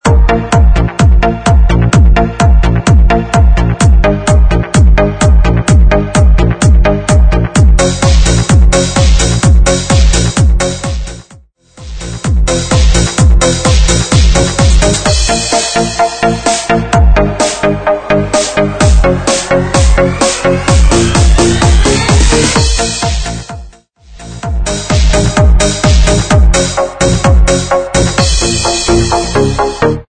128 BPM
Pumping Electronic